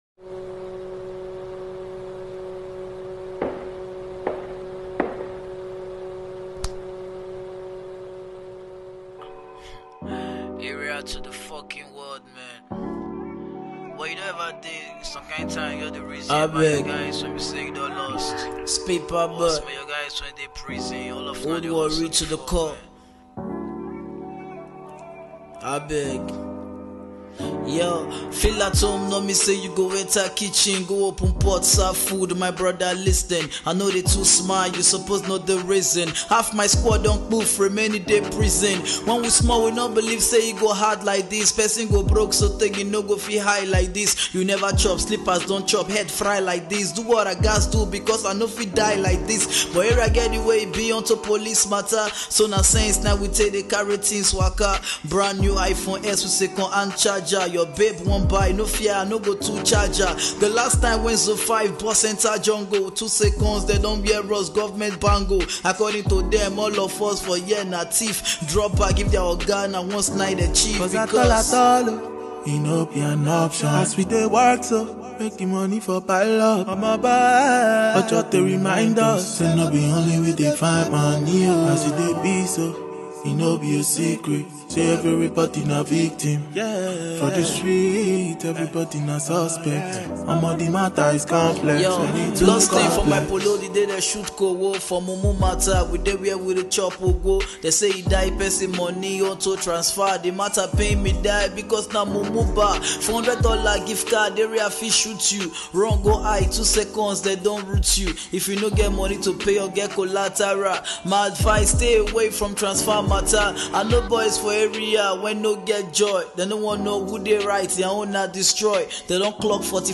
broken English rapper